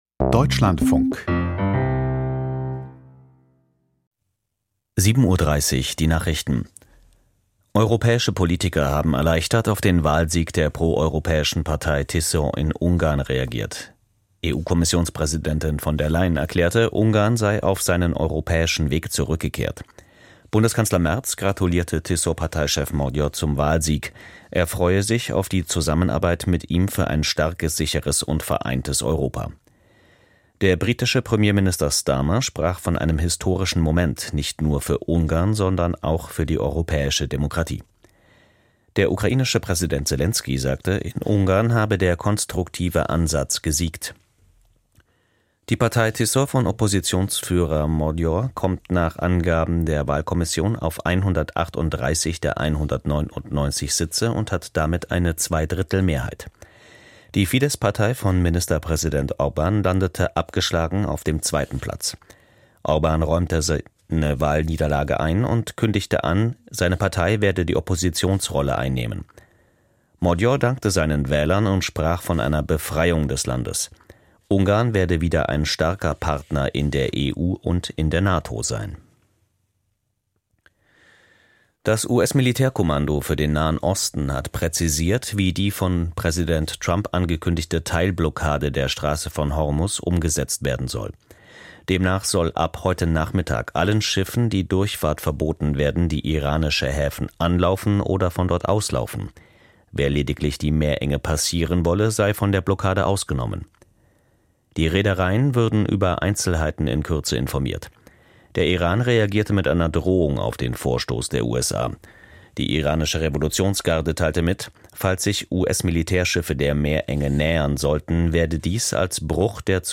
Die Nachrichten vom 13.04.2026, 07:30 Uhr